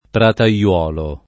prataiolo [ prata L0 lo ]